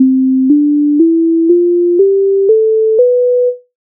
Major mode
MIDI файл завантажено в тональності C-dur
Standartni_poslidovnosti_C_dur_mode.mp3